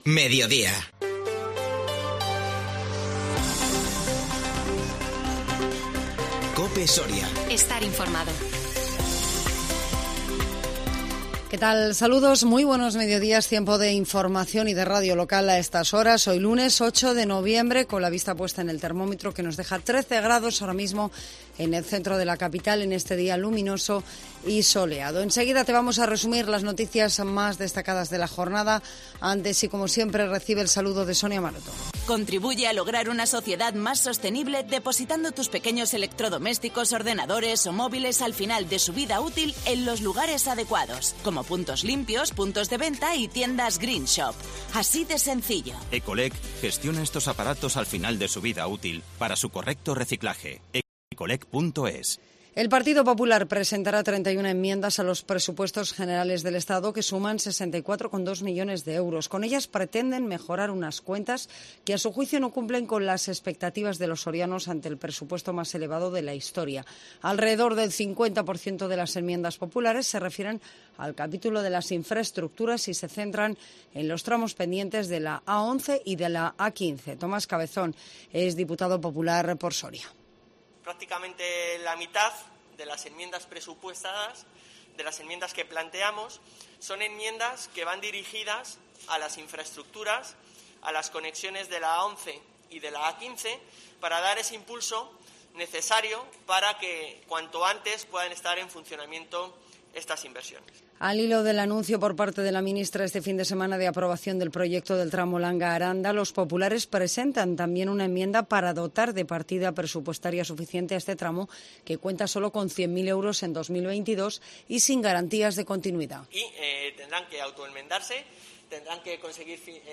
INFORMATIVO MEDIODÍA 8 NOVIEMBRE 2021